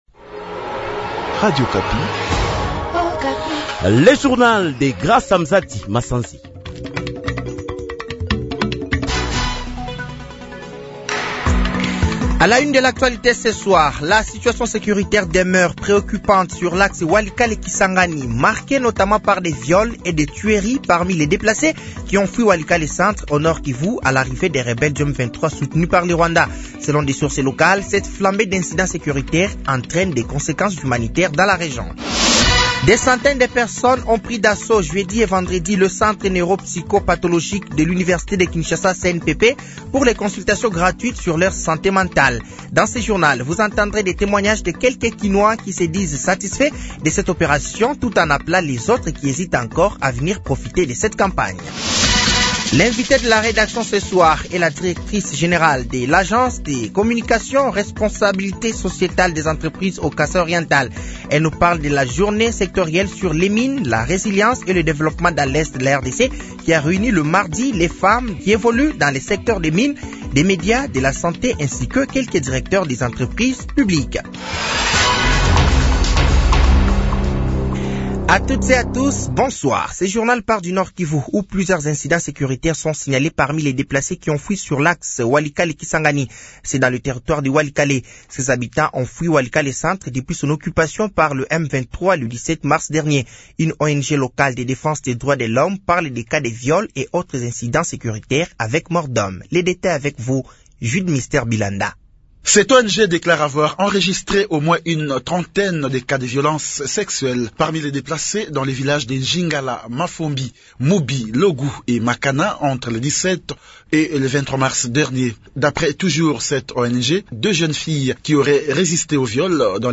Journal Soir
Journal français de 18h de ce samedi 29 mars 2025